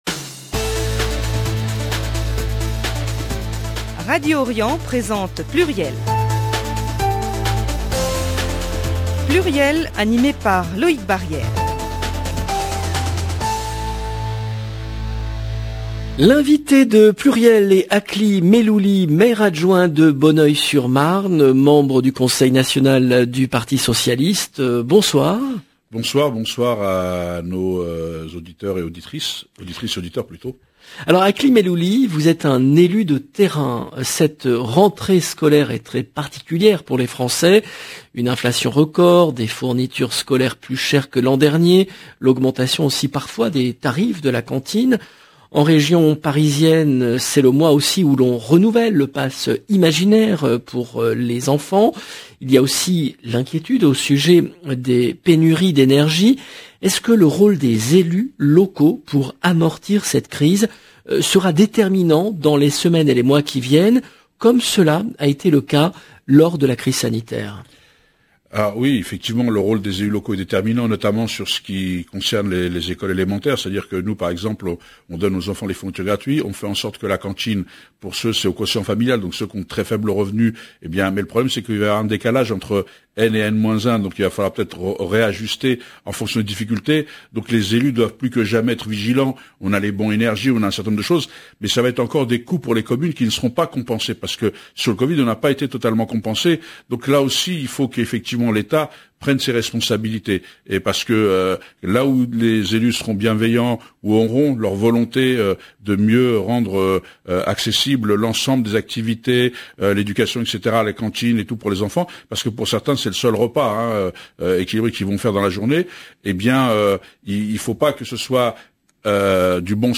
le rendez-vous politique du vendredi 2 septembre 2022 L’invité de PLURIEL est Akli Mellouli, Maire-adjoint de Bonneuil-sur-Marne, membre du Conseil national du Parti Socialiste.